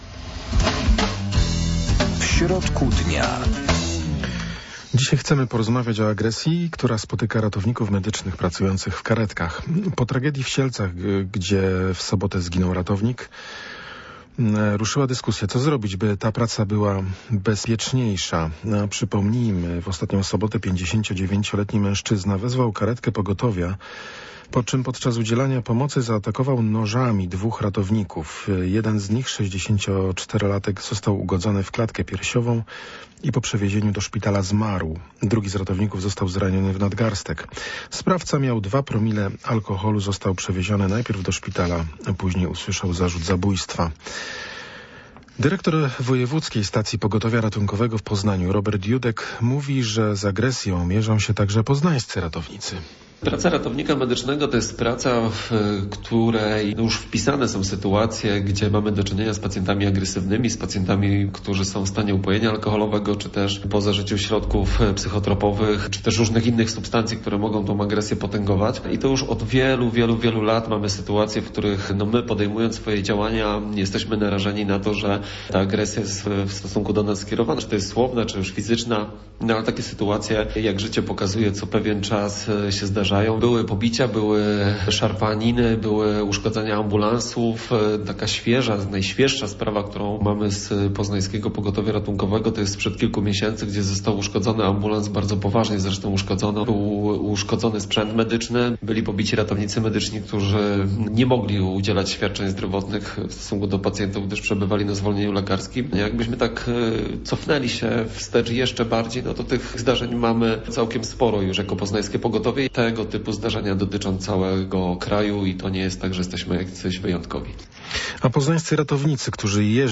Czy w sytuacji zagrożenia życia ratownicy powinni mieć możliwość użycia gazu albo paralizatora? Już dziś o 12.15 dyskusja na ten temat w audycji W środku dnia.